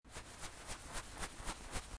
walking sounds implemented
steps.wav